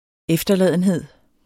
Udtale [ ˈεfdʌˌlæˀðənˌheðˀ ]